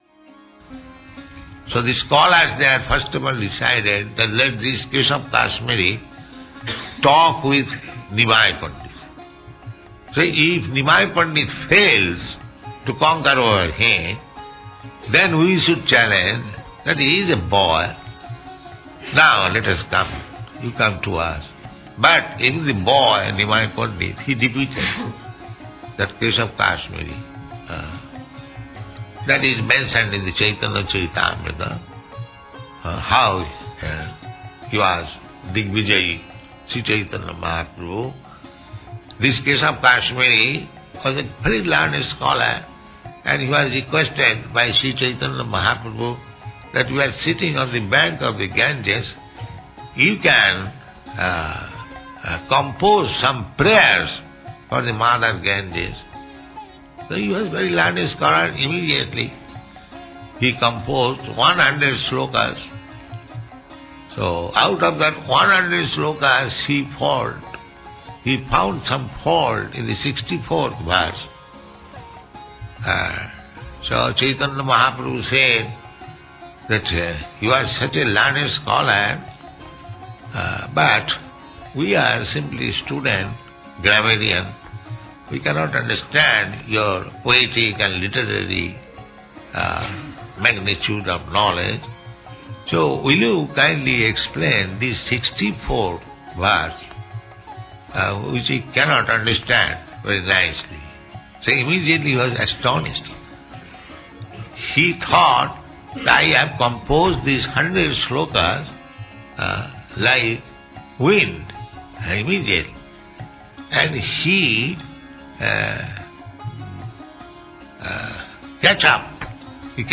(740108 - Lecture SB 01.16.11 - Los Angeles)